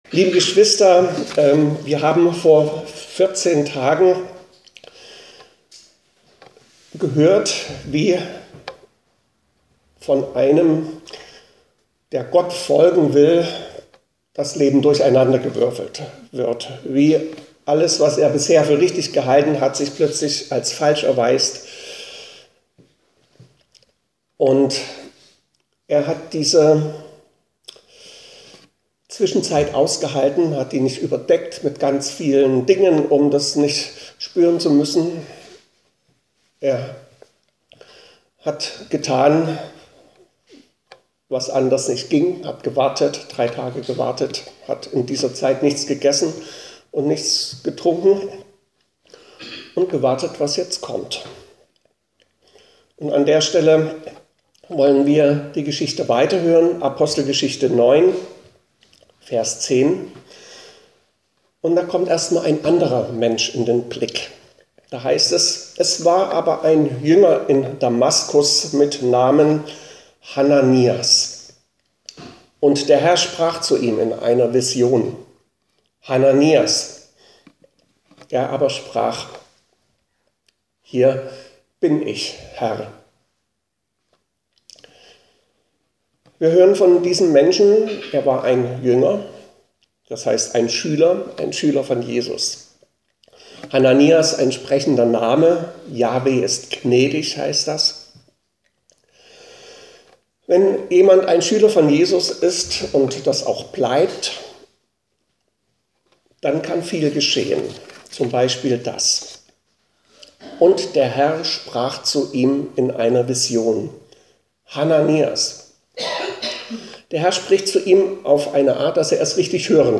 Predigten - Evangelisch-Freikirchliche Gemeinde Berlin Pankow (Niederschönhausen)